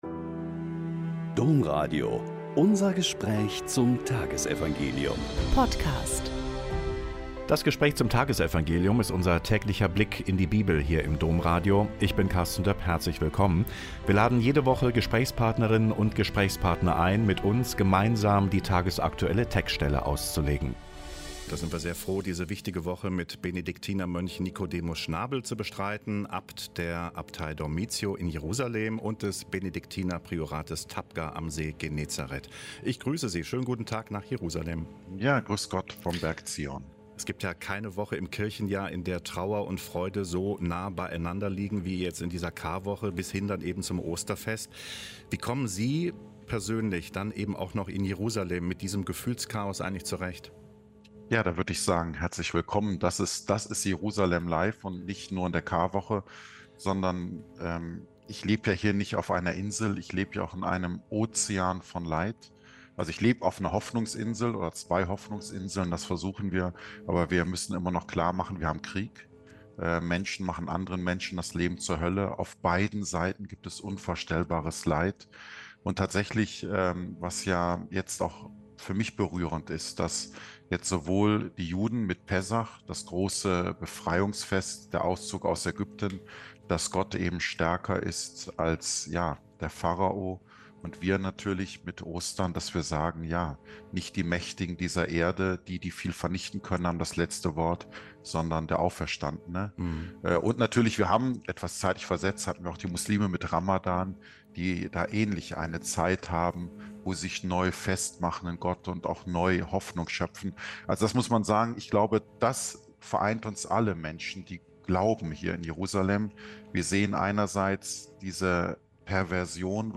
Mt 26,14-25 - Gespräch mit Abt Nikodemus Schnabel OSB